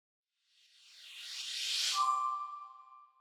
soul pickup long.wav